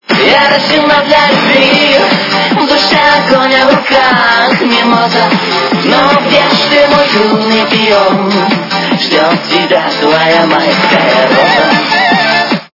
украинская эстрада
качество понижено и присутствуют гудки